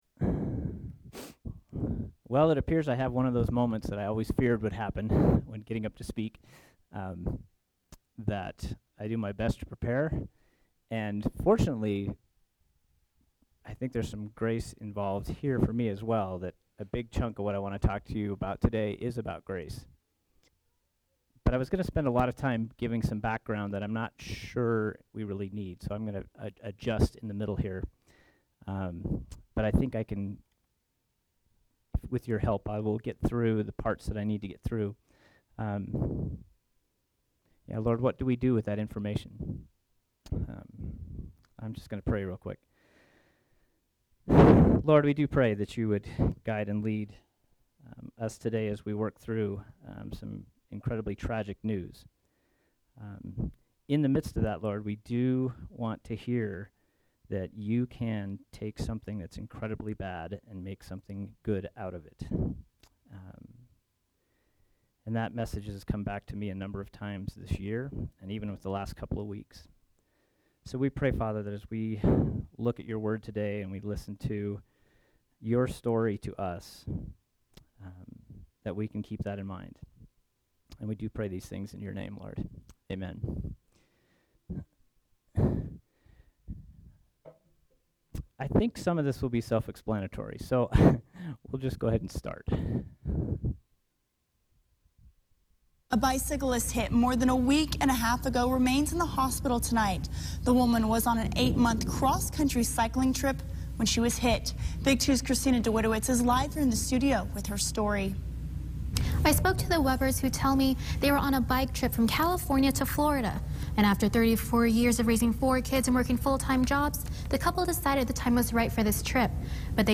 SERMON: Between a Rock and God’s Grace